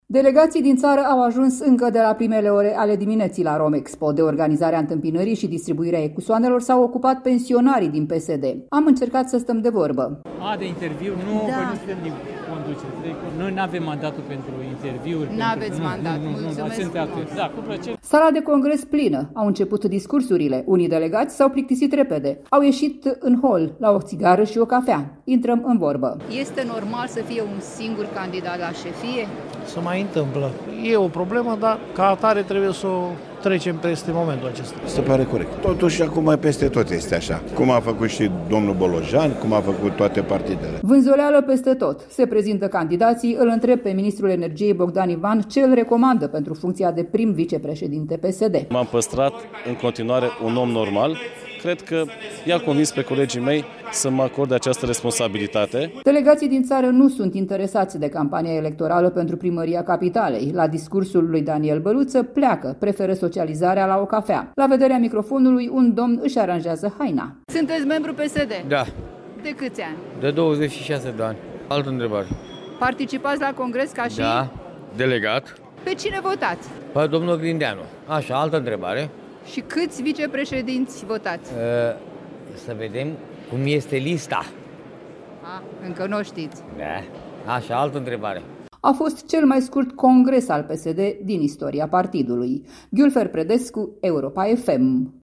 Romexpo București. 3500 de delegați din toată țara au venit vineri să își voteze noul lider PSD.
Unii delegați s-au plictisit repede, au ieșit în hol, la o țigară și cafea.
În sală, vânzoleală.